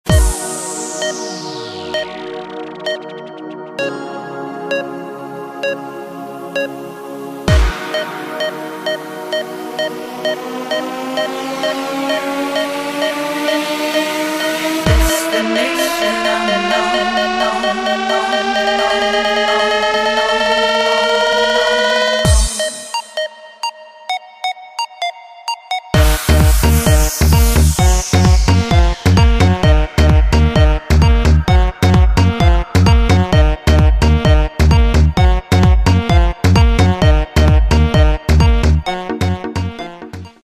громкие
женский голос
dance
Electronic
EDM
электронная музыка
нарастающие
Саксофон
house